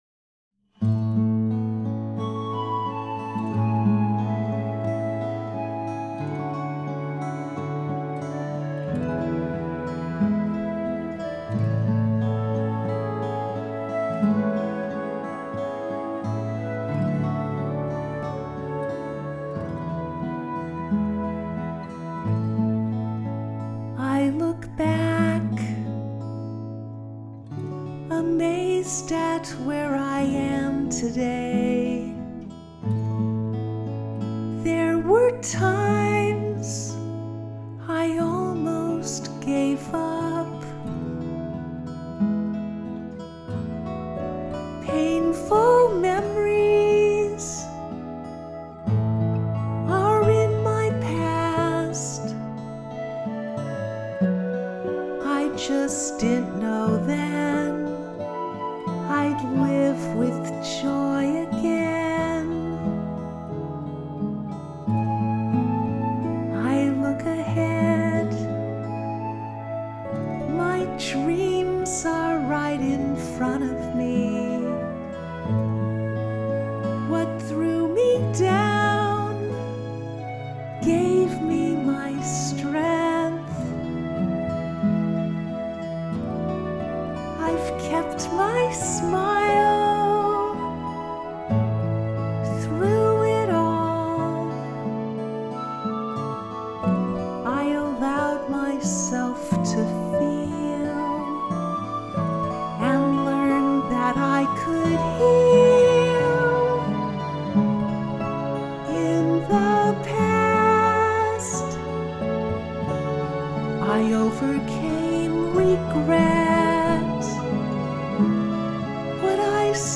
Let these three heartfelt vocal songs inspire you with their touching messages.